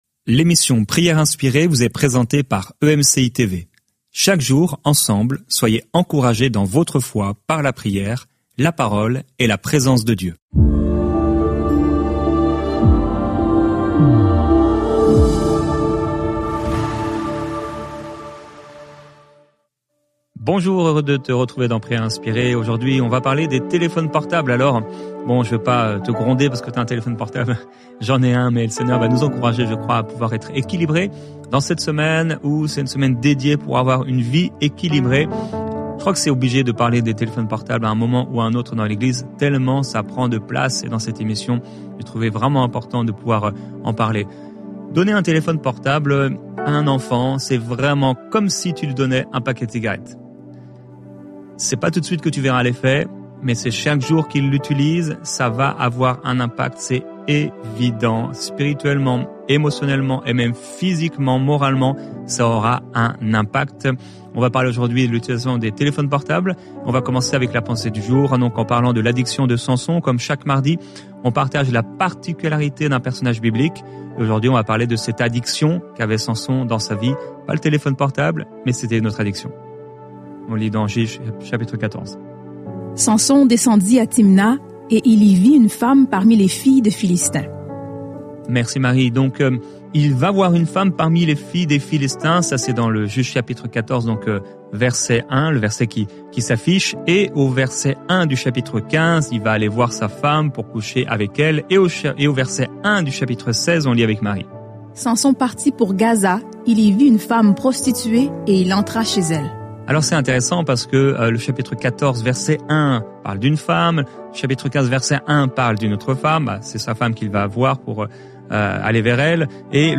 ⬇ TÉLÉCHARGER L'APPLICATION L'émission quotidienne « Prières inspirées » a pour but de rapprocher les croyants du cœur de Dieu, par la prière, des encouragements et des chants.